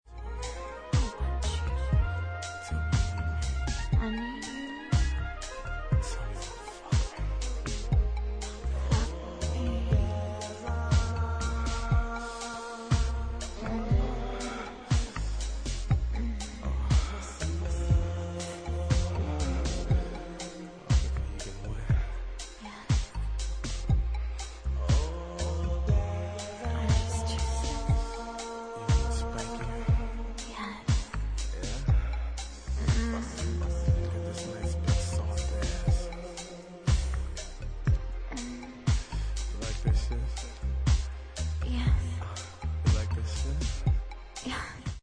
House Chicago